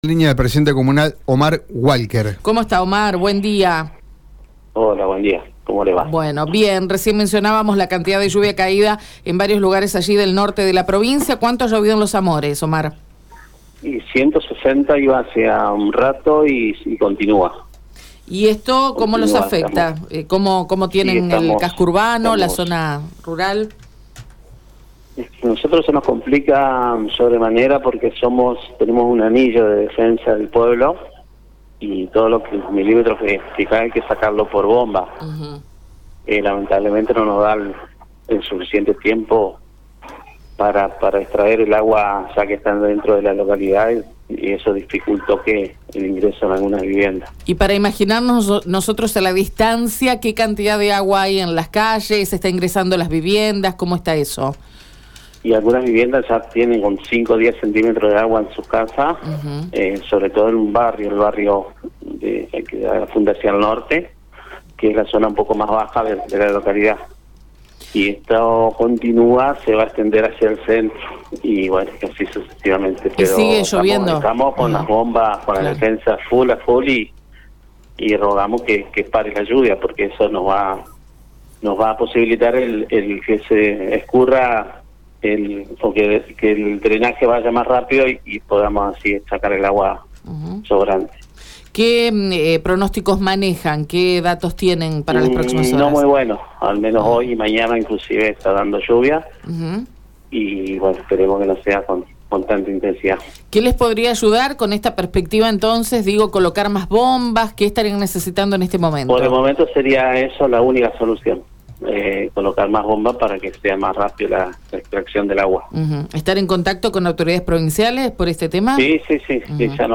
Escucha la palabra de Omar Walker en Radio EME:
INFORMADOS-OMAR-WALKER-PRESIDENTE-COMUNAL-DE-LOS-AMORES.mp3